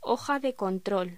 Locución: Hoja de control
voz